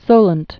(sōlənt)